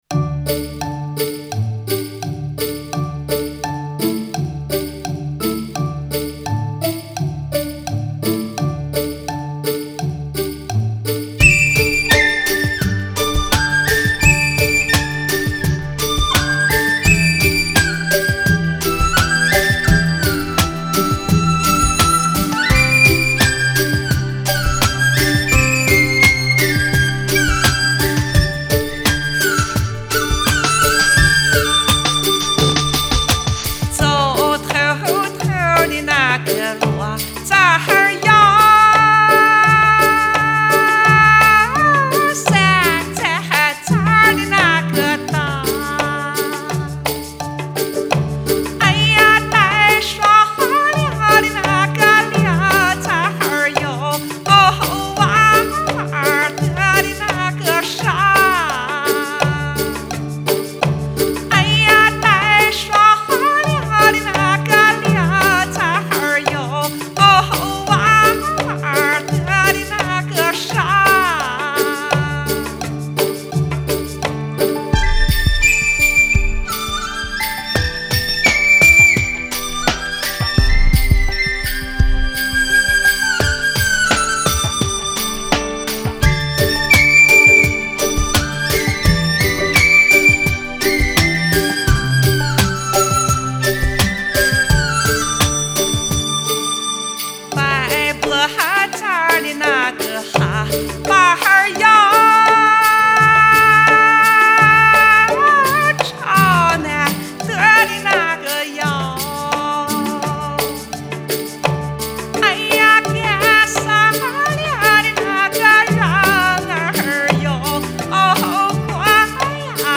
声音很特别，支持！
乡土味特浓，真喜欢这张专辑！
原汁原味的西部民歌，喜欢。